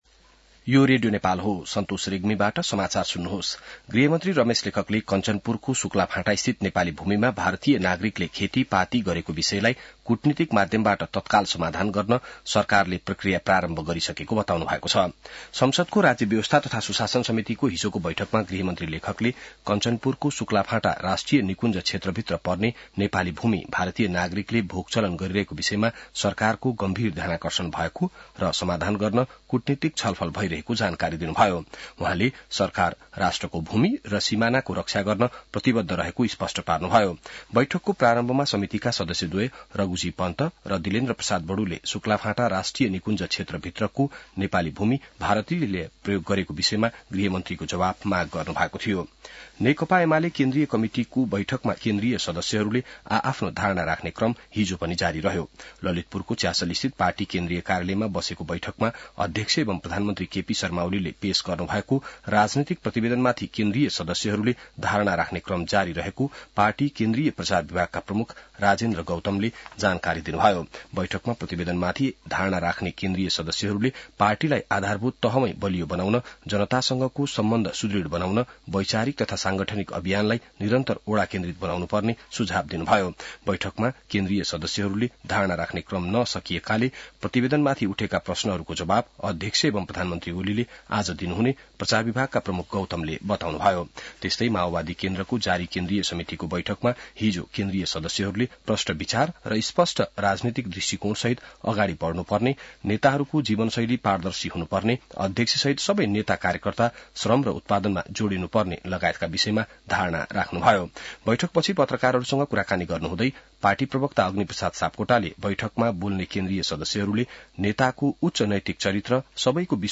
बिहान ६ बजेको नेपाली समाचार : २४ पुष , २०८१